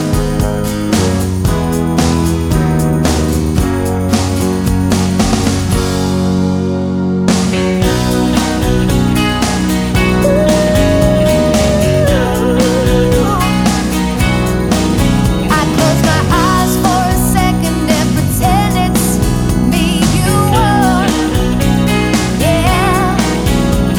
no Backing Vocals Easy Listening 3:27 Buy £1.50